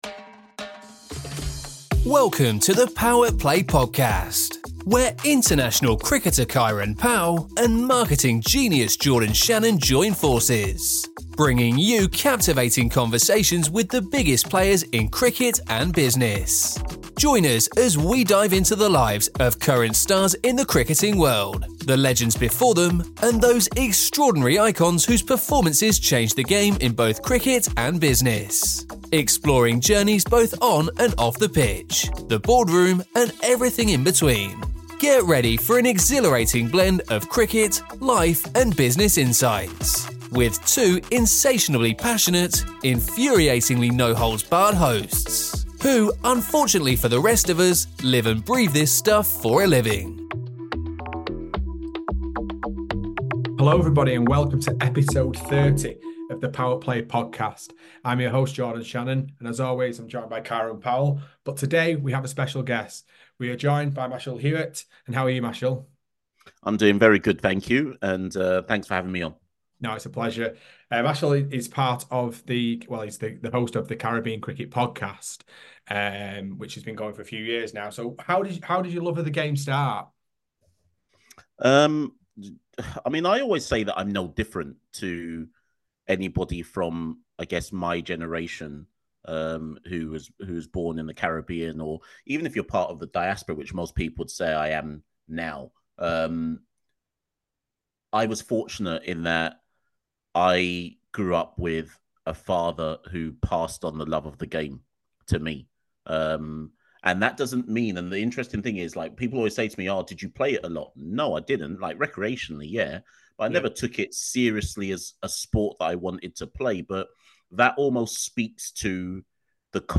With expert analysis and lively conversation, this episode is a must-listen for cricket fans and anyone interested in the vibrant world of Caribbean cricket.